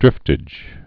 (drĭftĭj)